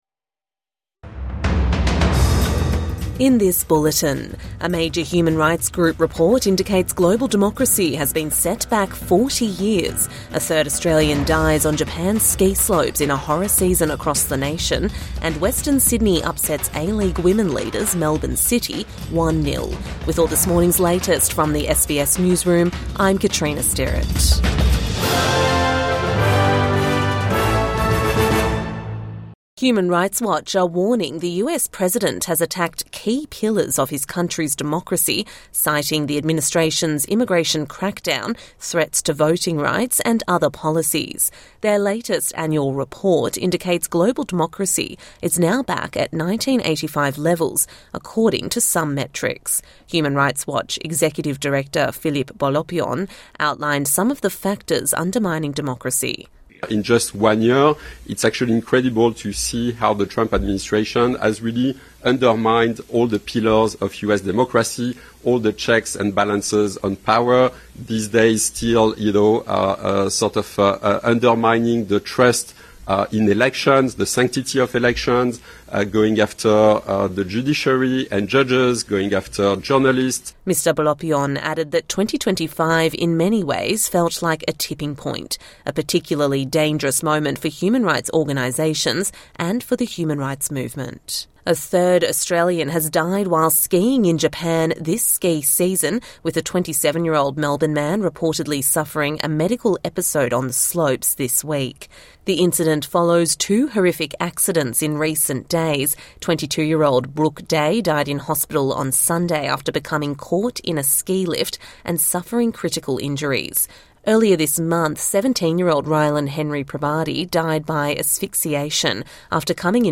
Human Rights Watch says the US has undermined democracy | Morning News Bulletin 5 February 2026